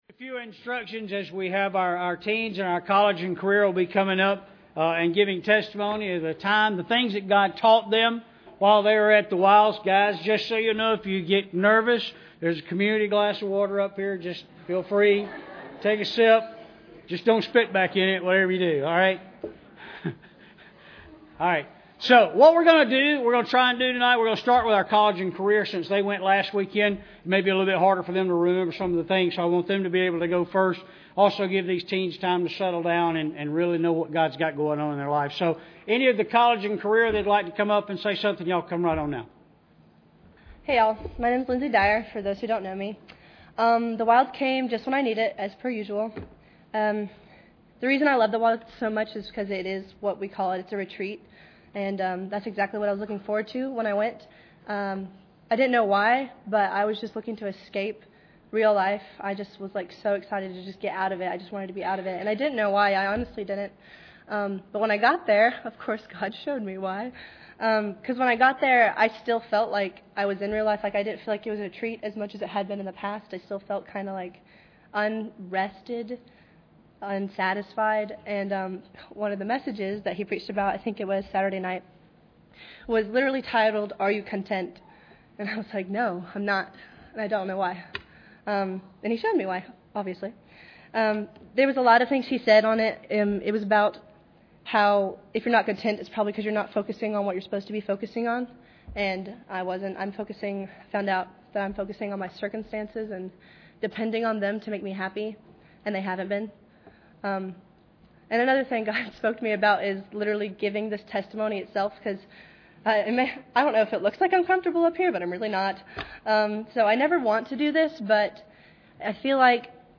Preacher: CCBC Members | Series: General